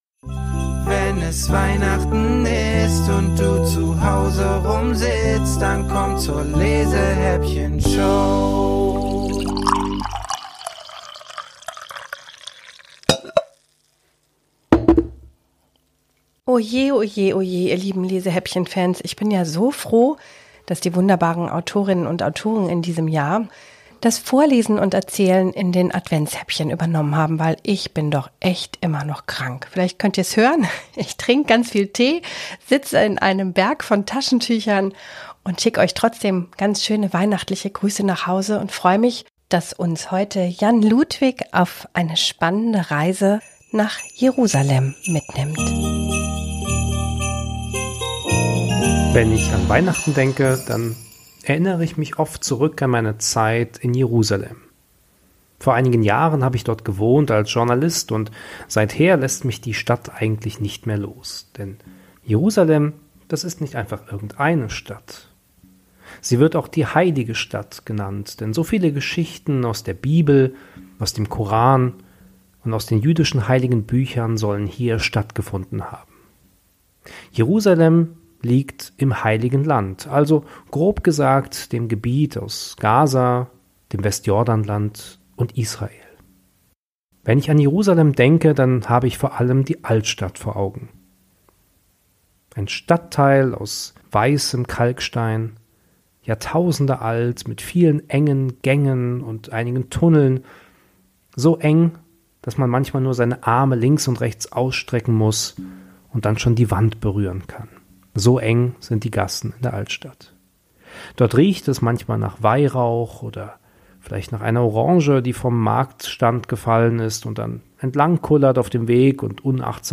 Autorinnen und Autoren höchstpersönlich vertont wurden!